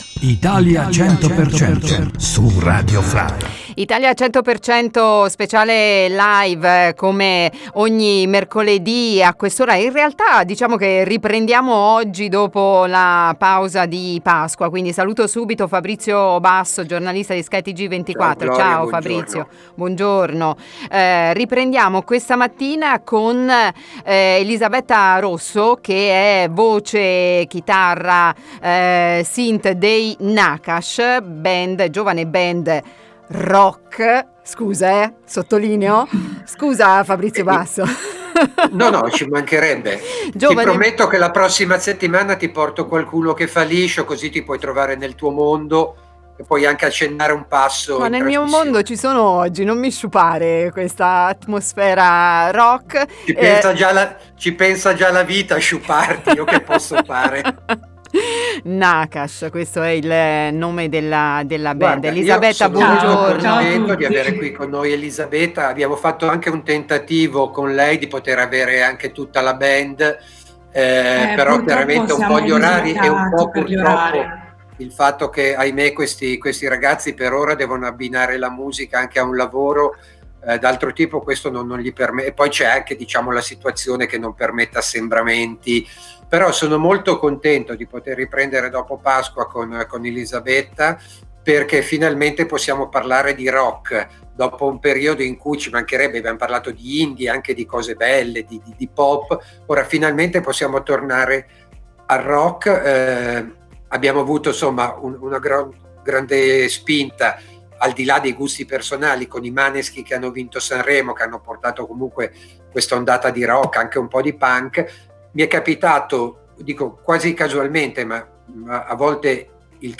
Ospiti del quattordicesimo appuntamento della nuova stagione di 100% Italia LIVE, la rubrica dedicata alle novità discografiche italiane, saranno i Nakhash.